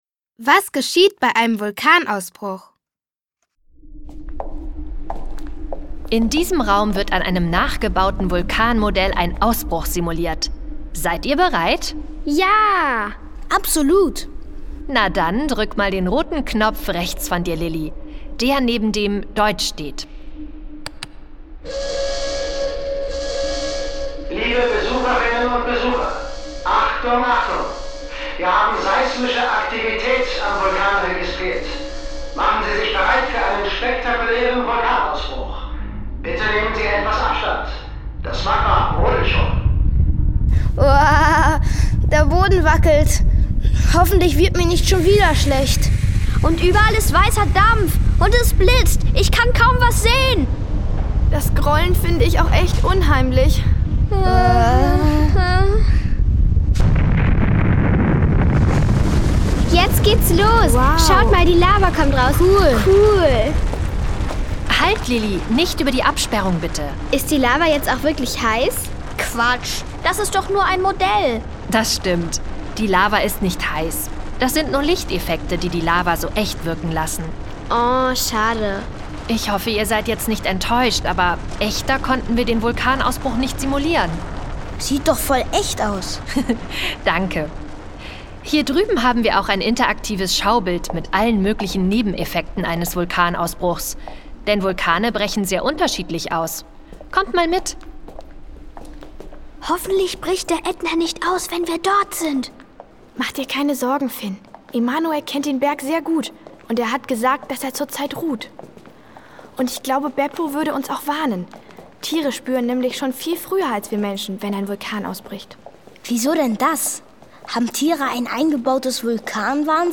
Beim Blick über den Kraterrand erfahren sie Faszinierendes über die Entstehung, den Einfluss und den Nutzen dieser feuerspeienden Berge. Umfassendes Sachwissen, authentische Geräusche, viel Musik und das Eröffnungslied "Was wollt ihr wissen?" sorgen für ein spannendes HörErlebnis.
Schlagworte Erdbeben • Kindersachbuch • Krater • Lava • Natur • Sachhörbuch • Sachthemen für Kinder • Sachwissen für Kinder • Sizilien • Tsunamis • Wissenswertes für Kinder